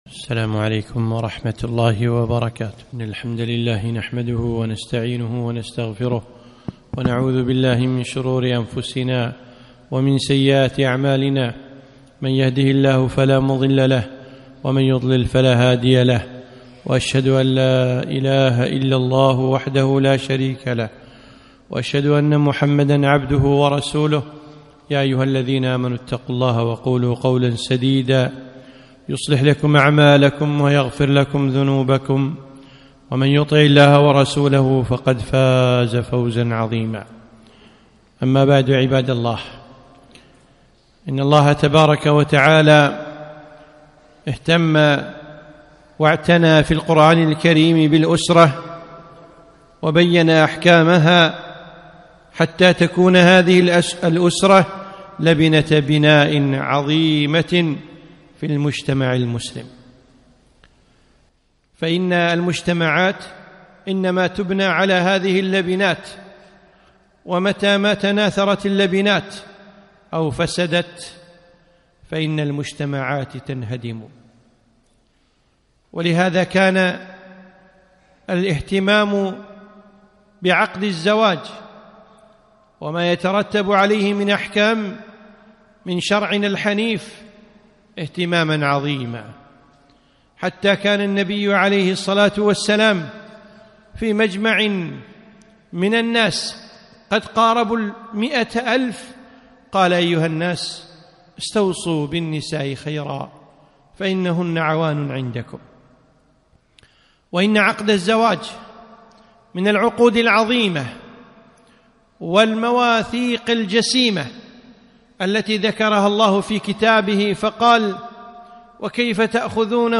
خطبة - حتى لا يقع الطلاق